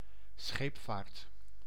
Northern Standard[56][57]
It is laxer than in English, has a graver friction and is sometimes labialized.
Nl-scheepvaart.ogg.mp3